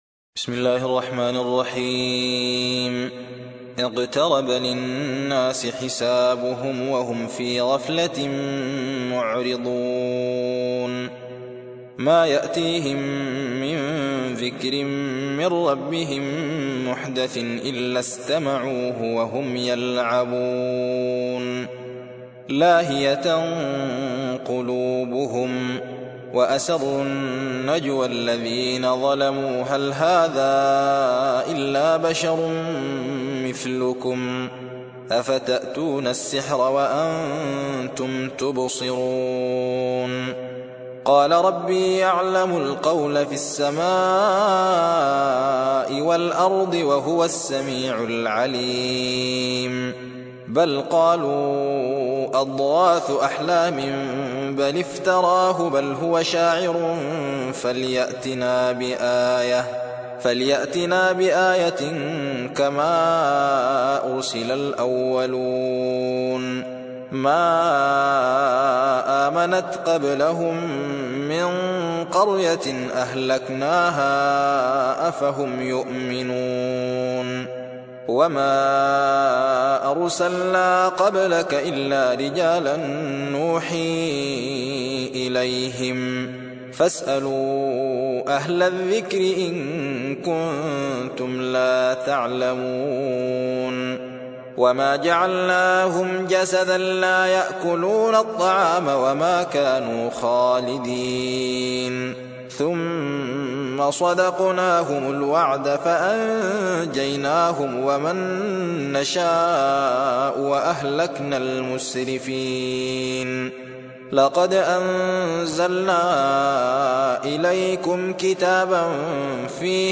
21. سورة الأنبياء / القارئ